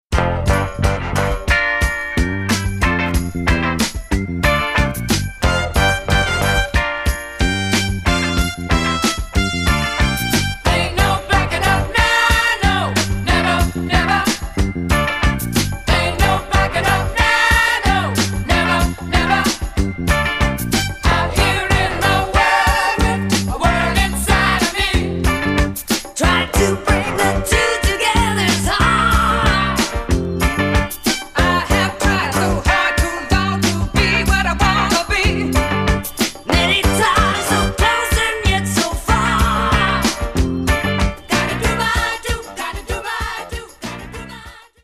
FREE SOUL/RARE GROOVE
ギター